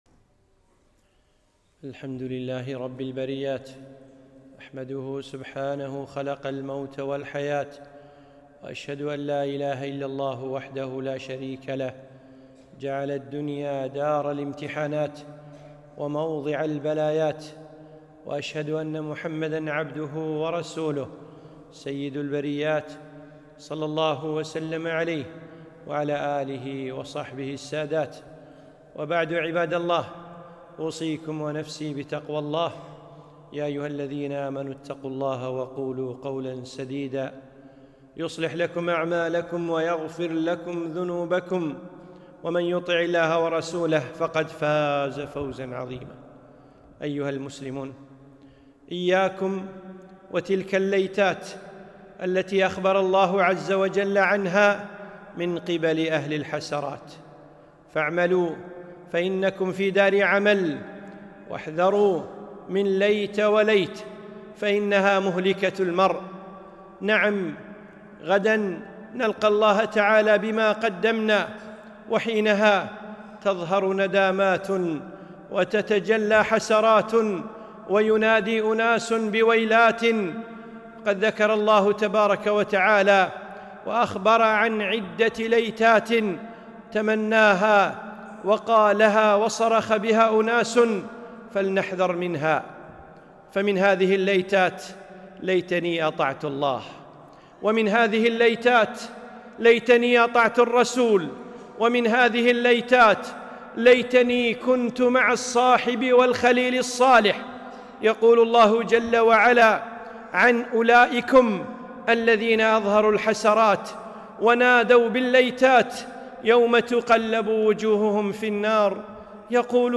خطبة - إياك والليتات